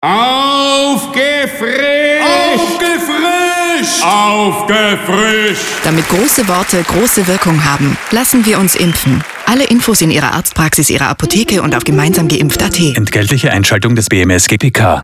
Radio spots